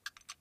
clock-ticking-4.ogg